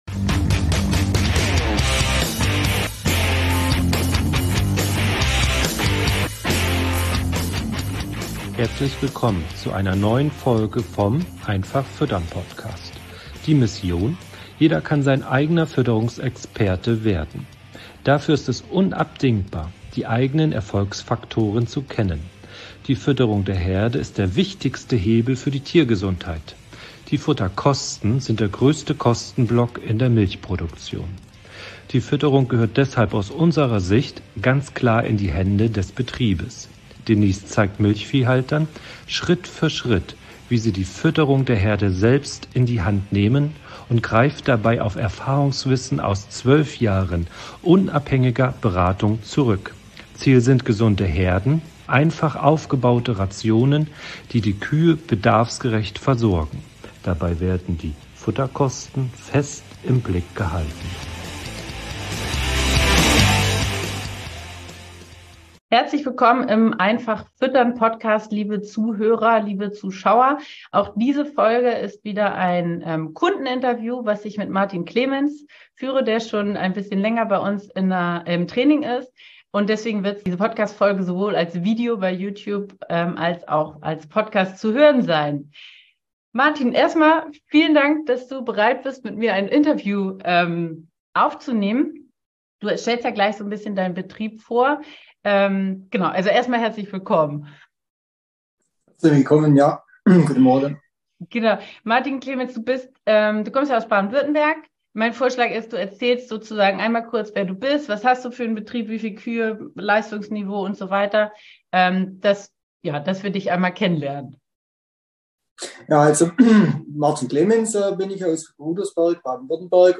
Kundeninterview